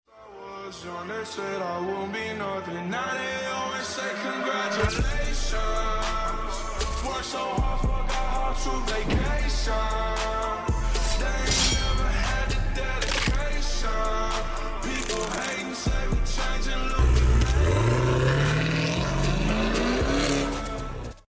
Maserati Levante Trofeo💨 Twin turbo sound effects free download